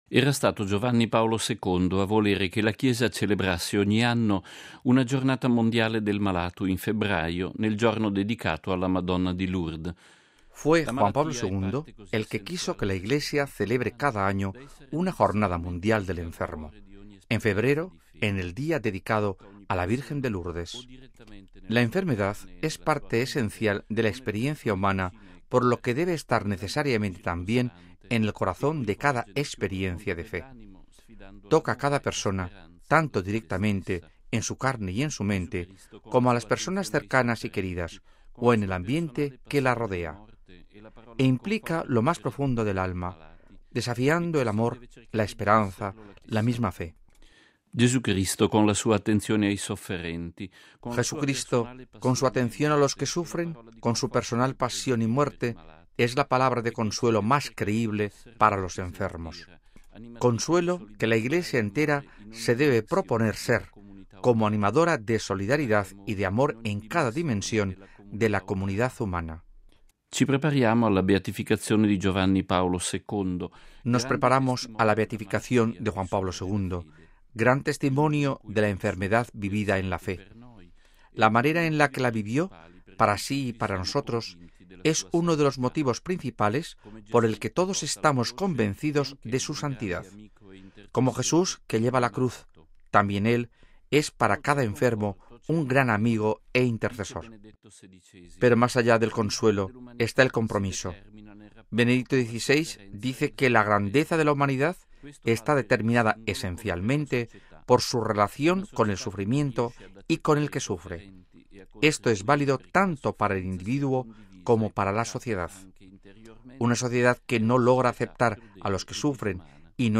Editorial ‘Octava dies’: “Enfermos e Iglesia”
Sábado, 19 feb (RV).- “Enfermos e Iglesia”, es el titulo del editorial del Padre Federico Lombardi, para el informativo semanal “Octava Dies” del Centro Televisivo Vaticano.